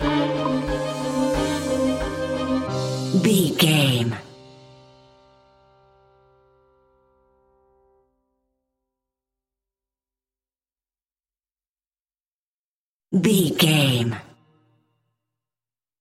Aeolian/Minor
ominous
haunting
eerie
piano
percussion
synthesizer
creepy
horror music